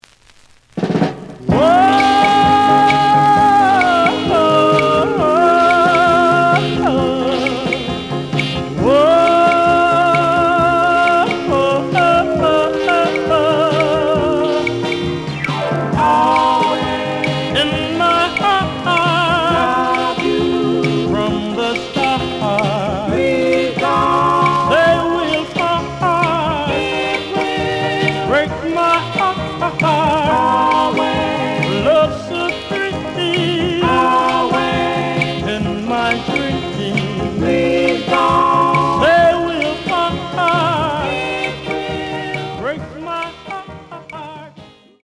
試聴は実際のレコードから録音しています。
●Genre: Rhythm And Blues / Rock 'n' Roll
●Record Grading: VG (両面のラベルにダメージ。盤に若干の歪み。プレイOK。)